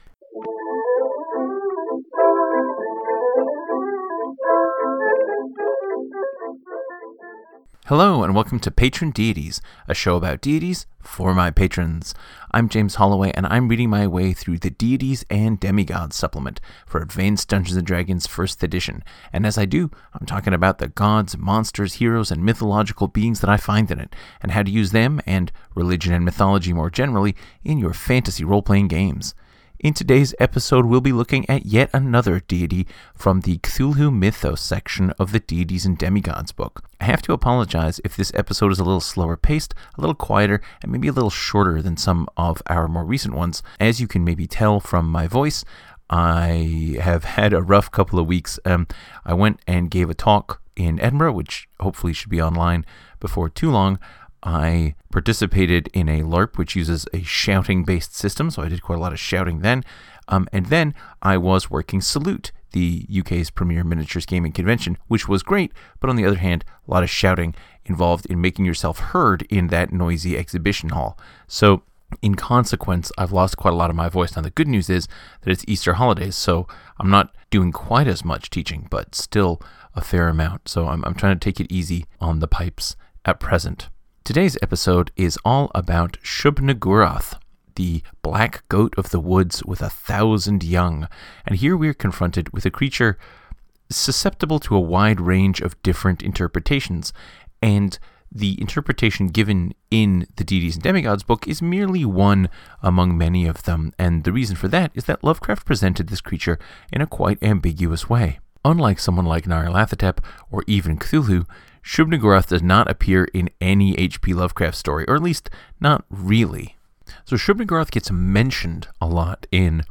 This episode is a little short because my voice is not fully recovered.